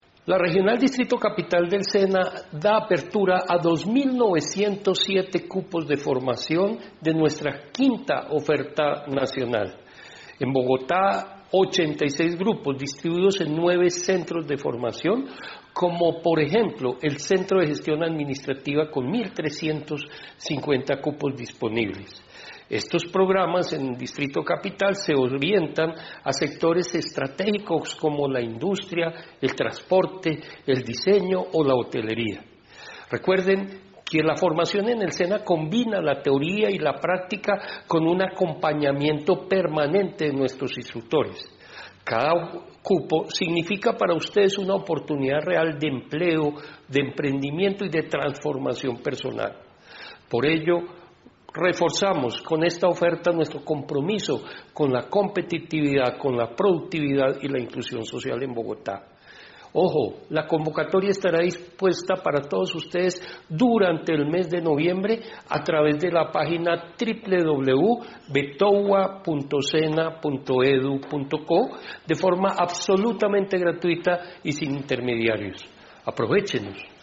Gerardo Medina, Director (e) Regional Distrito Capital, anuncia la convocatoria gratuita.